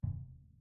Orchestral Bass
bdrum_muted_pp_rr2.wav